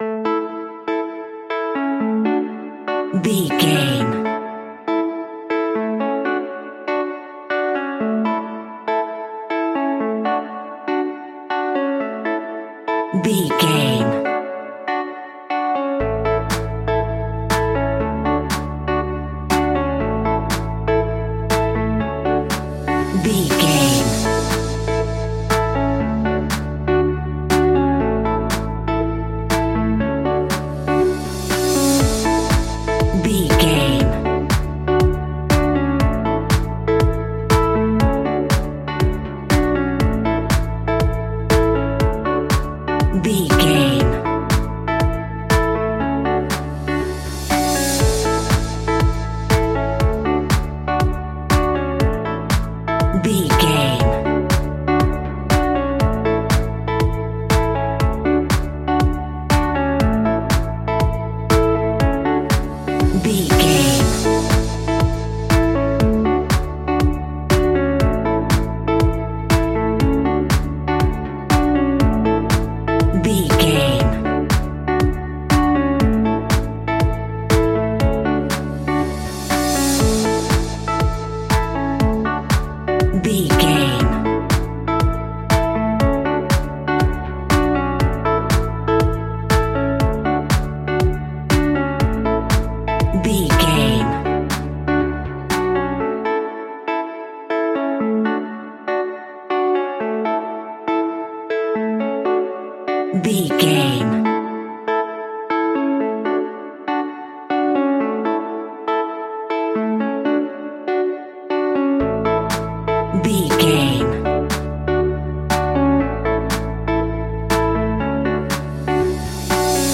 Ionian/Major
groovy
energetic
uplifting
hypnotic
electric guitar
bass guitar
drum machine
funky house
deep house
nu disco
clavinet
synth bass
horns